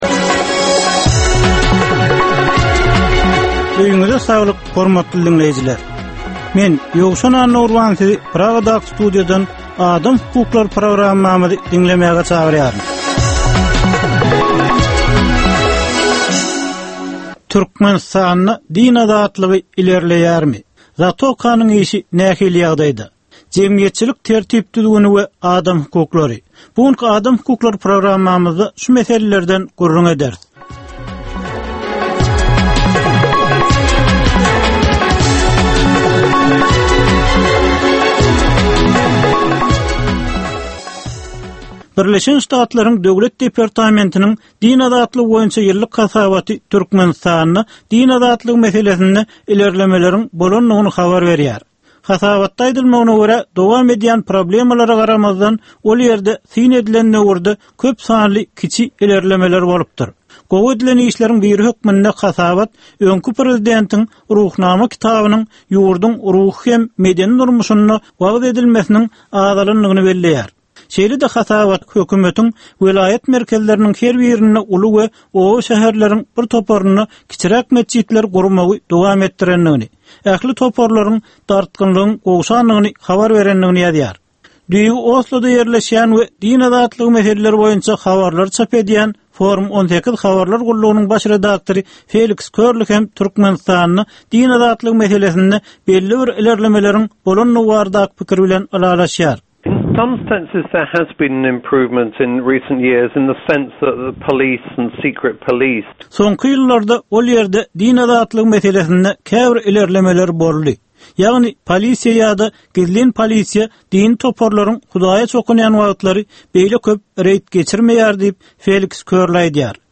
Türkmenistandaky ynsan hukuklarynyň meseleleri barada 15 minutlyk ýörite programma. Bu programmada ynsan hukuklary bilen baglanyşykly anyk meselelere, problemalara, hadysalara we wakalara syn berilýar, söhbetdeşlikler we diskussiýalar gurnalýar.